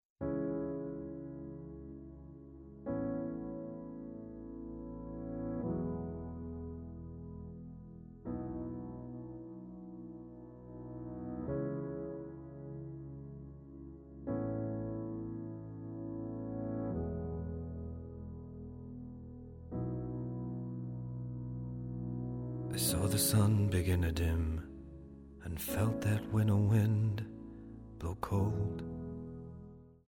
Tonart:C#m Multifile (kein Sofortdownload.
Die besten Playbacks Instrumentals und Karaoke Versionen .